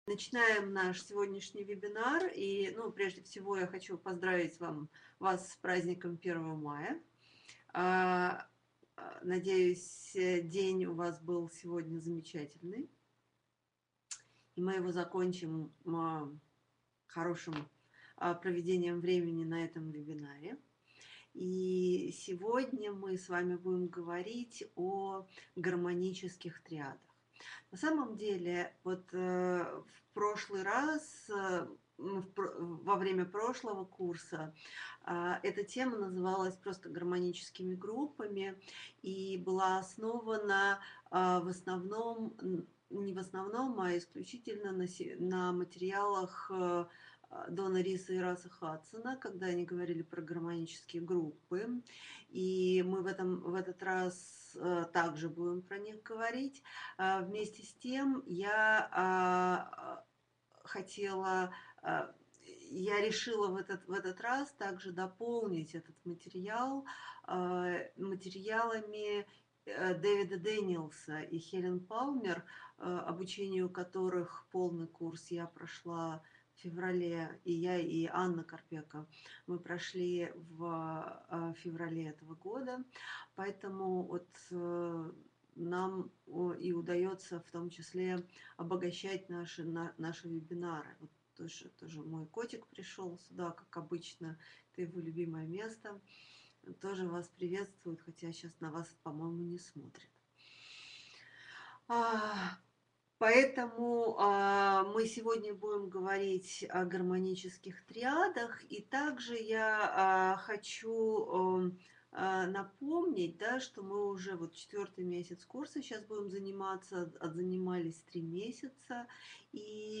Аудиокнига Гармонические группы | Библиотека аудиокниг